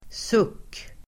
Ladda ner uttalet
suck substantiv, sigh Uttal: [suk:] Böjningar: sucken, suckar Definition: djupt (hörbart) andetag som uttryck för sorg el. lättnad etc Exempel: dra en djup suck (heave a deep sigh), en suck av lättnad (a sigh of relief)